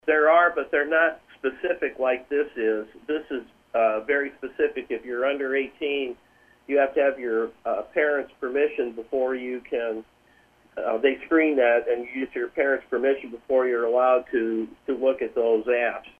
Rep. Jeneary says the bill came about because of a contact which came to a legislator’s daughter on social media